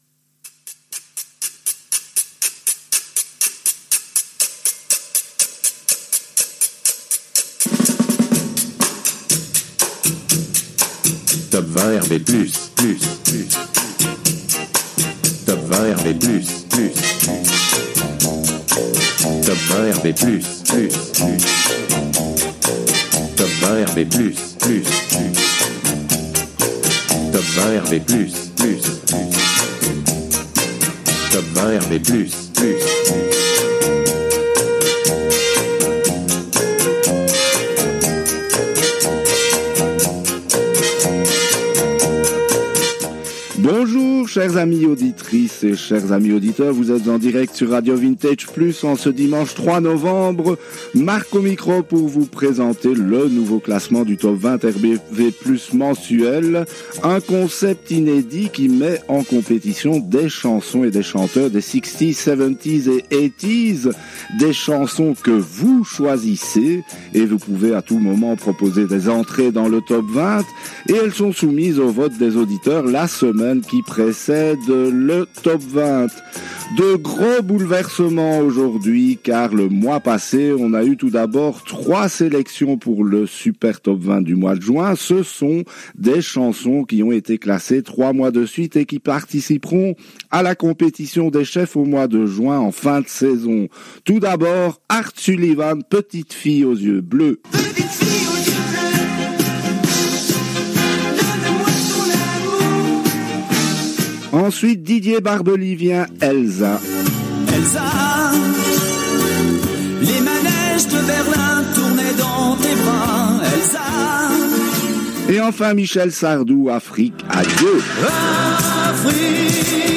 diffusée le dimanche 02 octobre 2022 à 10h en direct des studios belges RV+